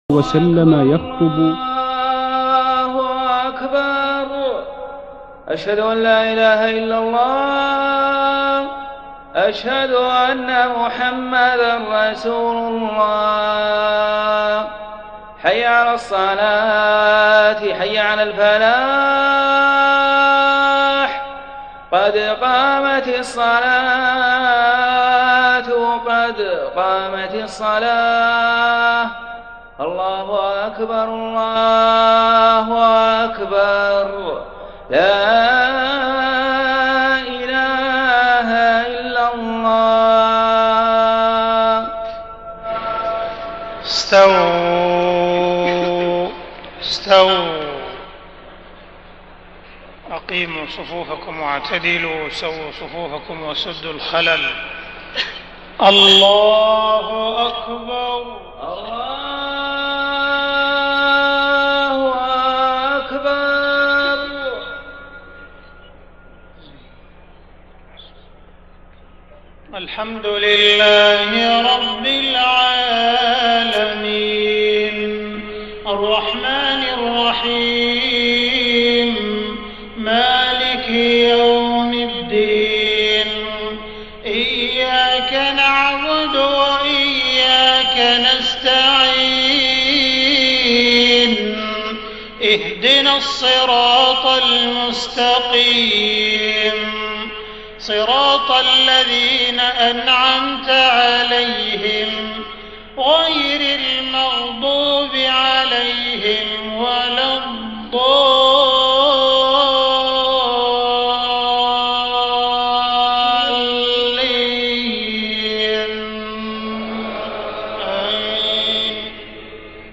صلاة المغرب 5 محرم 1430هـ سورتي الشرح والعصر > 1430 🕋 > الفروض - تلاوات الحرمين